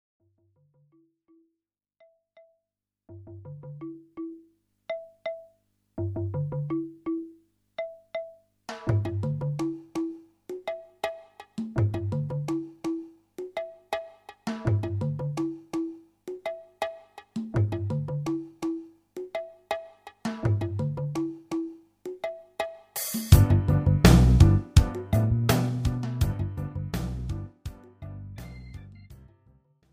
This is an instrumental backing track cover.
• Key – G
• Without Backing Vocals
• With Fade